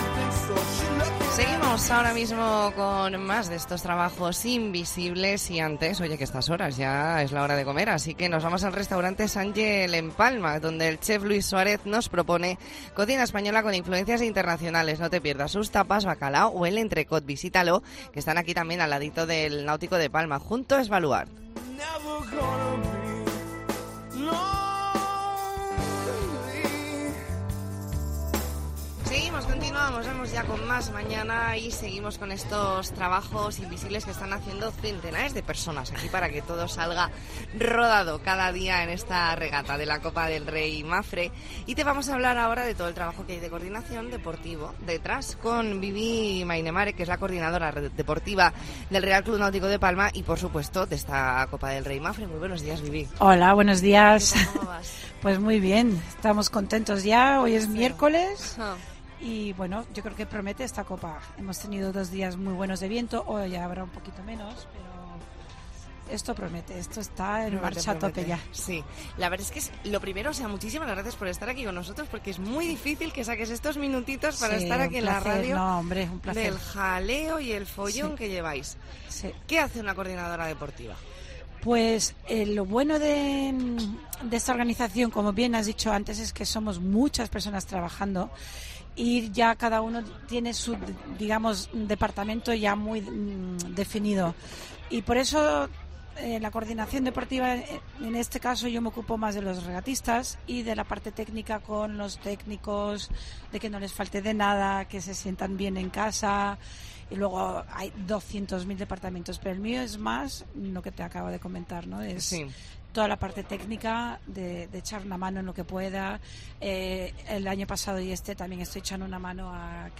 AUDIO: Especial La Mañana en COPE Más Mallorca desde el RCNP con motivo de la 40 Copa del Rey Mapfre
Entrevista en La Mañana en COPE Más Mallorca, miércoles 3 de agosto de 2022.